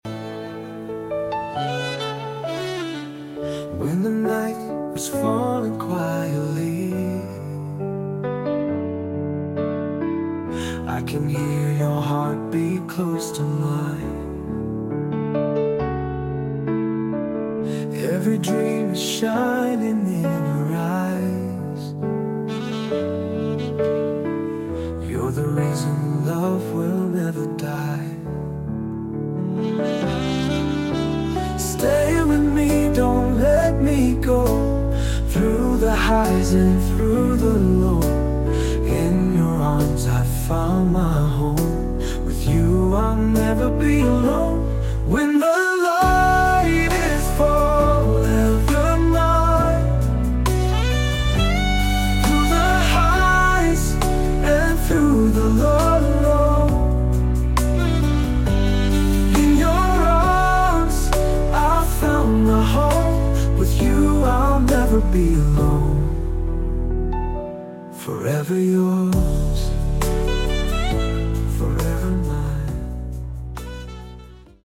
Música Romántica en Ingles